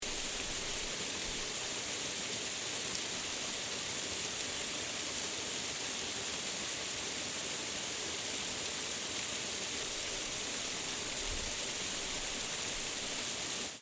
Waterfall (loud noise)
Duration - 16 s Environment - Waterfall, streams, mountains, light breeze, oncoming traffic at distance. Description -Waterfall, flowing, crushing, hitting rocks, stones below strong force,.